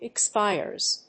発音記号
• / ɪˈkspaɪɝz(米国英語)
• / ɪˈkspaɪɜ:z(英国英語)